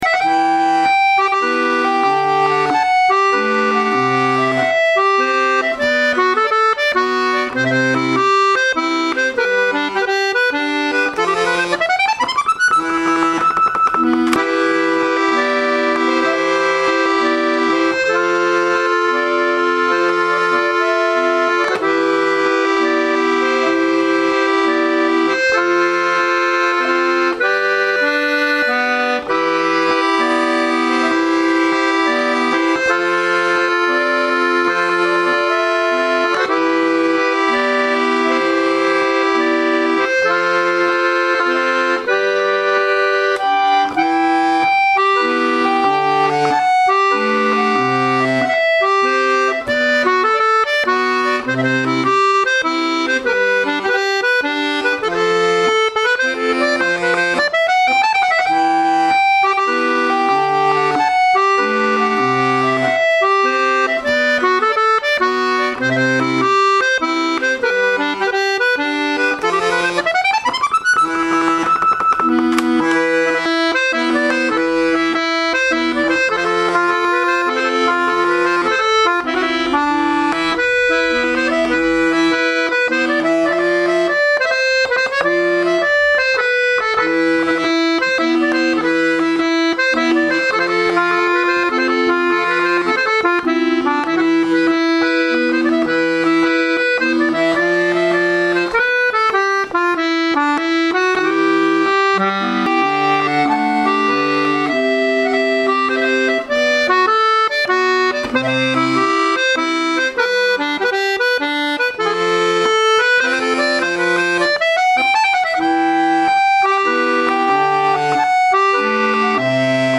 Répétition du 03/06/2011 - Module 3 - Bretagne/Dauphiné/Auvergne
mazurka folkloriade.mp3